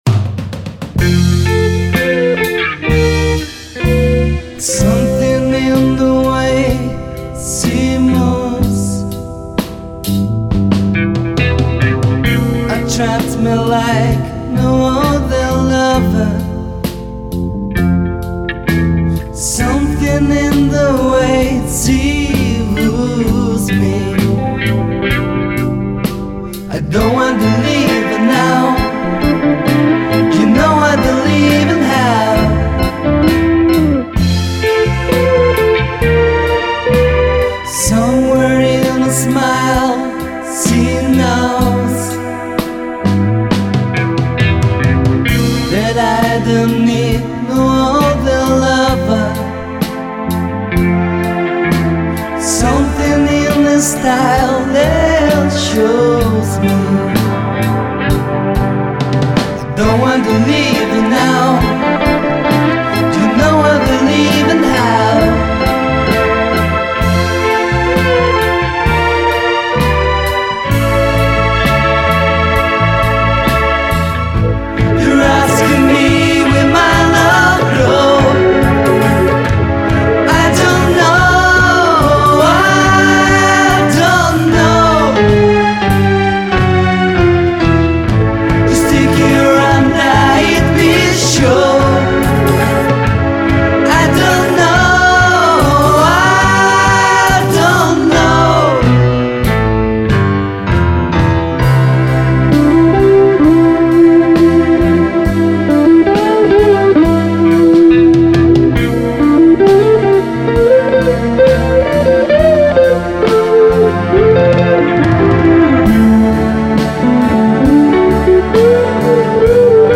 Grabado en estudio casero en 1994